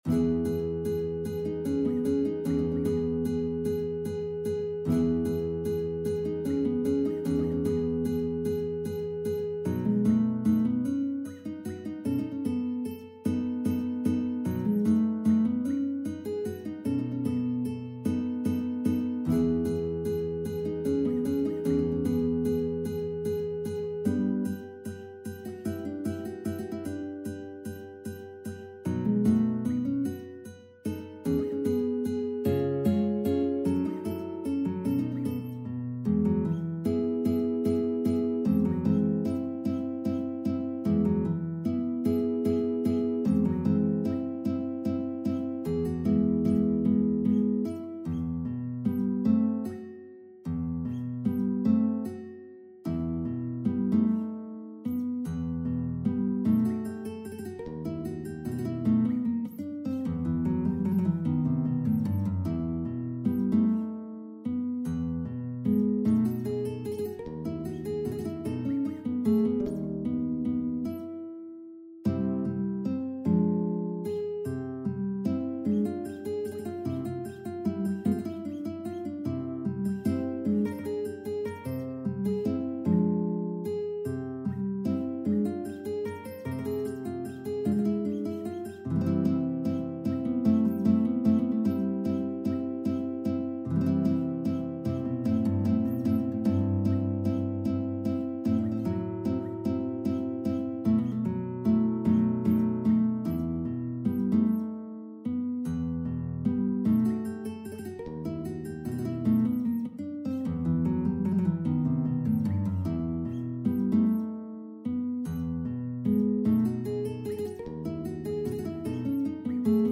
Classical Albéniz, Isaac Granada Guitar version
Guitar  (View more Intermediate Guitar Music)
Classical (View more Classical Guitar Music)